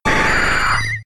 Cri de Roucarnage K.O. dans Pokémon X et Y.